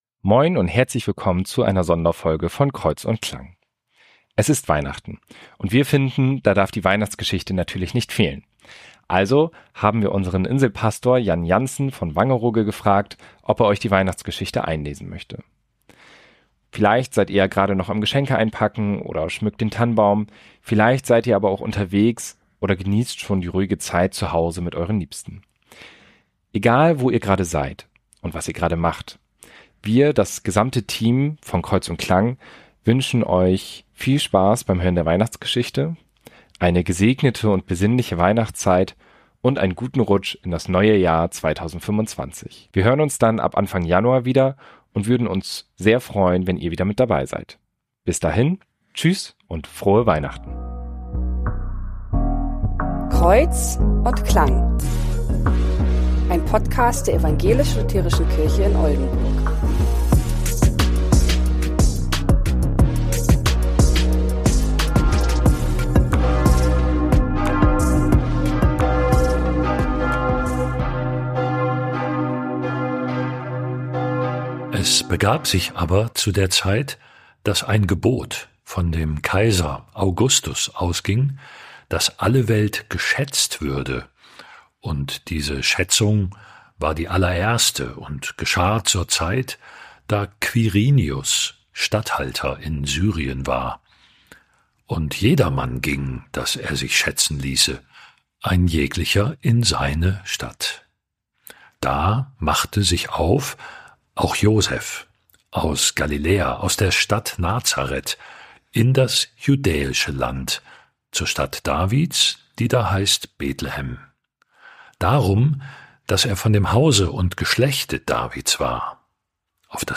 Für die Audioaufnahme der Kirchenglocken der St.